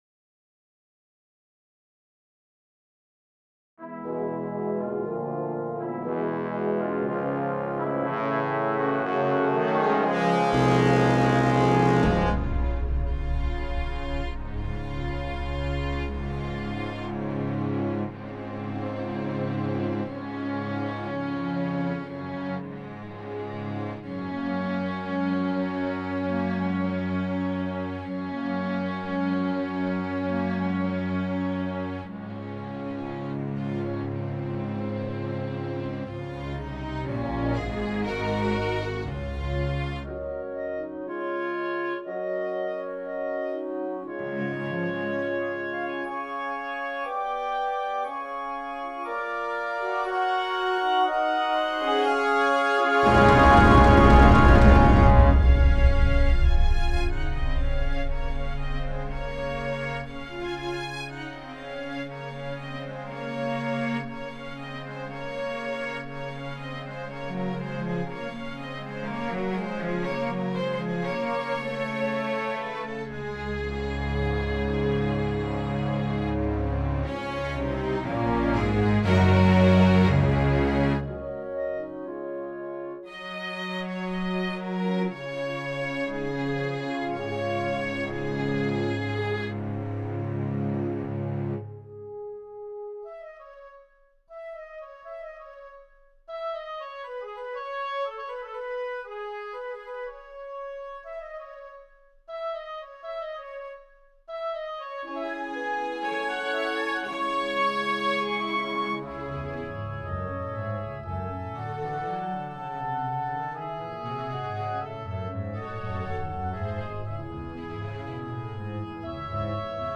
Der gute Klang der Instrumente machte die erste Verwirrung bei der Registrierung der 5 Software Titel schnell vergessen.